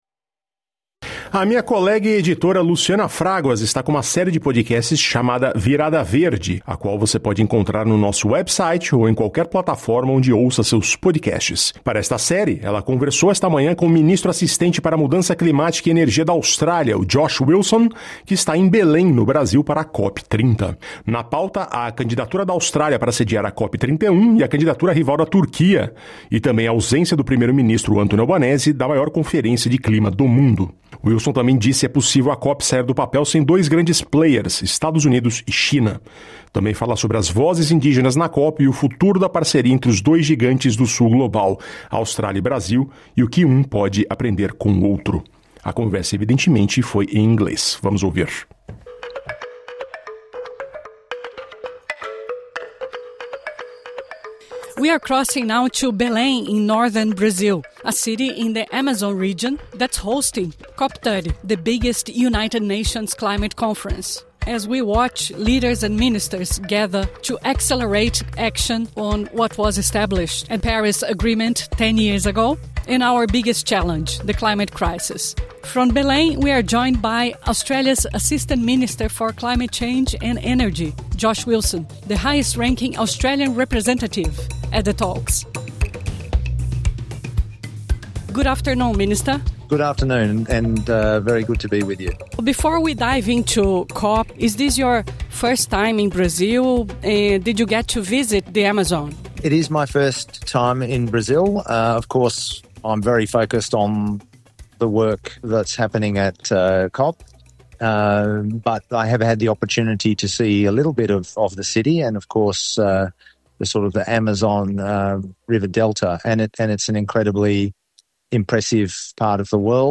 Direto de Belém, Josh Wilson, vice-ministro australiano para Mudança Climática e Energia fala sobre a candidatura da Austrália para sediar a COP31, elogia a liderança do Brasil e critica retrocessos no debate climático. A entrevista foi feita em inglês.
Ministro Asistente para o Clima, Josh Wilson no Pavilhão da Austrália na COP de Belém